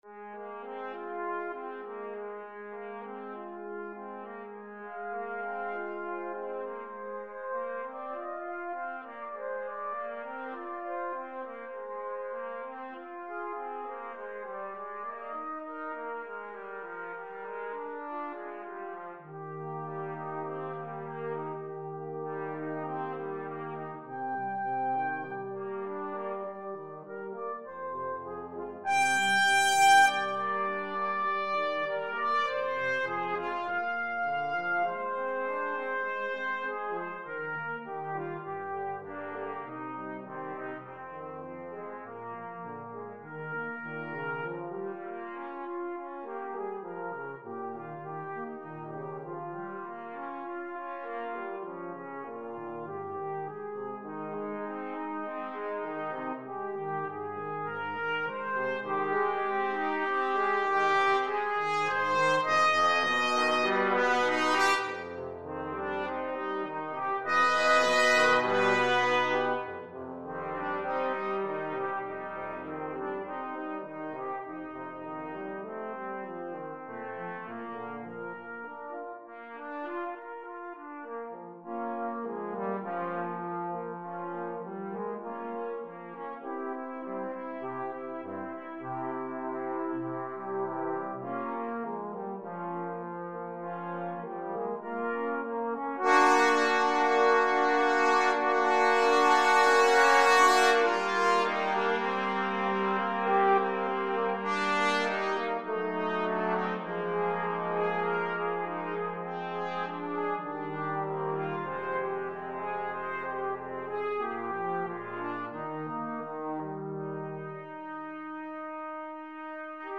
Trumpet 1Trumpet 2Trombone 1Trombone 2
4/4 (View more 4/4 Music)
Andantino sans lenteur (View more music marked Andantino)
Brass Quartet  (View more Advanced Brass Quartet Music)
Classical (View more Classical Brass Quartet Music)